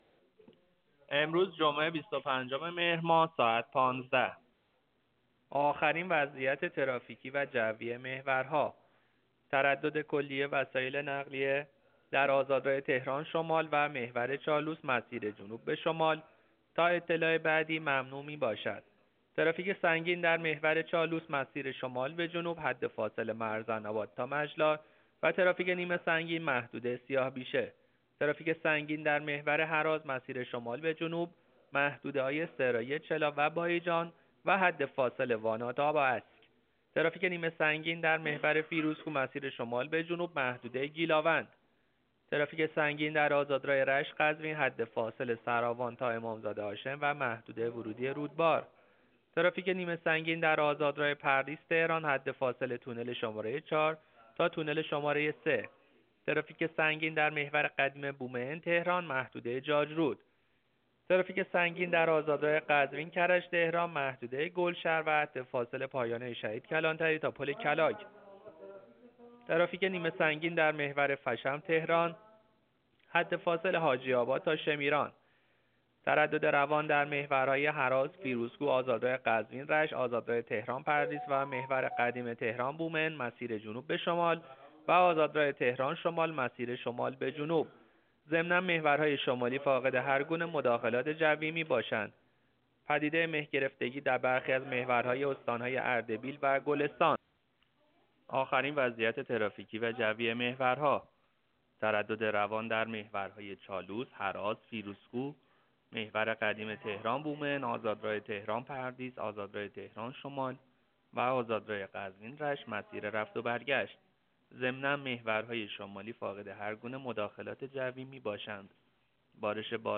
گزارش رادیو اینترنتی از آخرین وضعیت ترافیکی جاده‌ها ساعت ۱۵ بیست‌وپنجم مهر؛